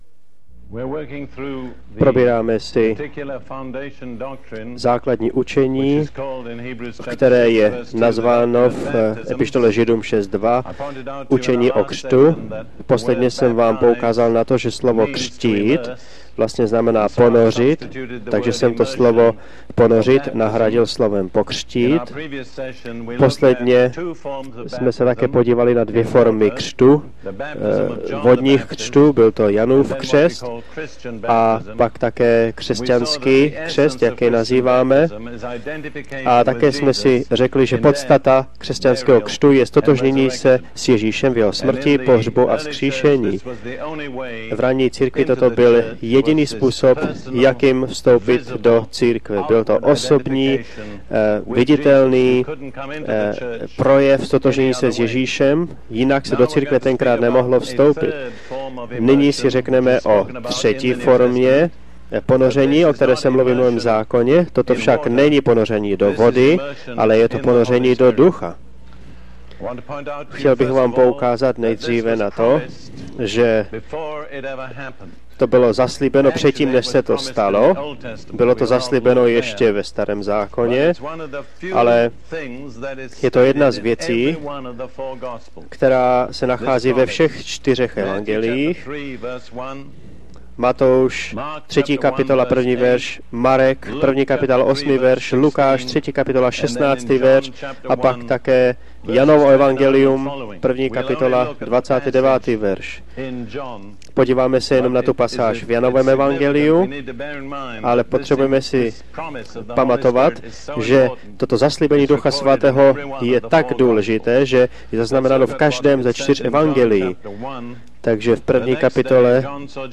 Z rádiového vysílání Dereka Prince s překladem do češtiny – Ponoření do Ducha svatého